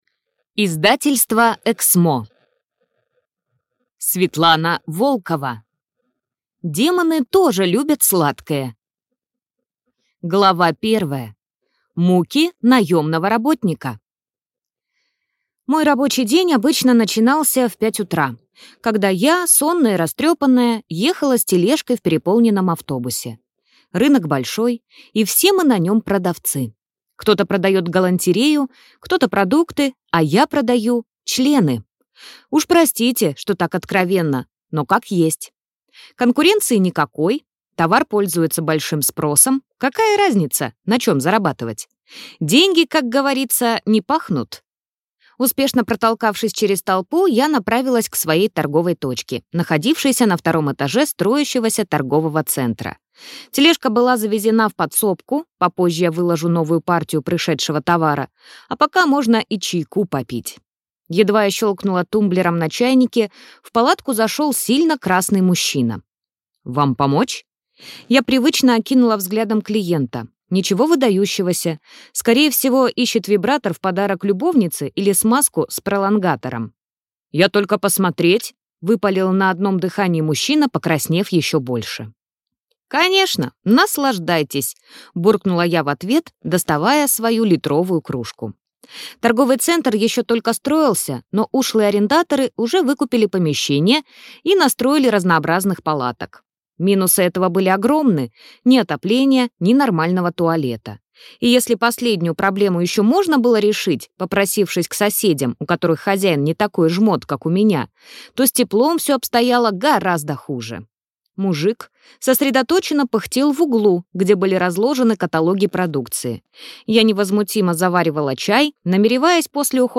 Аудиокнига Демоны тоже любят сладкое | Библиотека аудиокниг